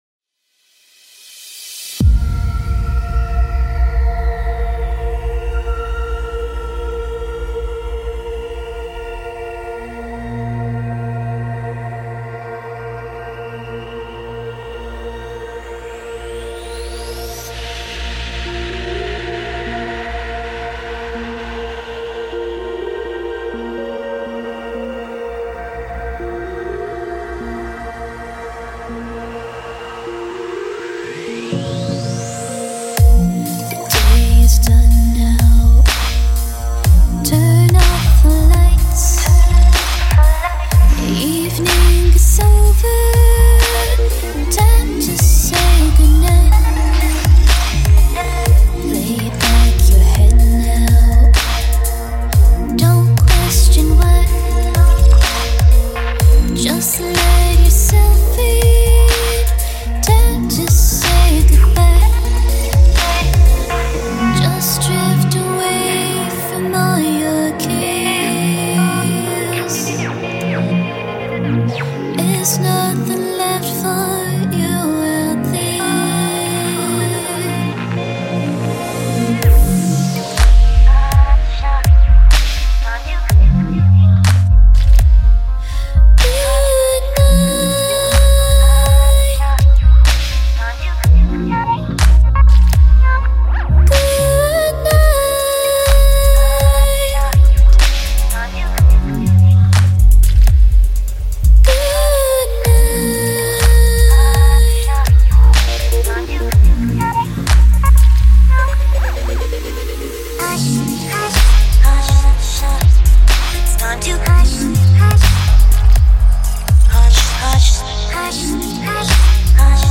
chillstep lulaby